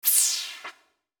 Download Space sound effect for free.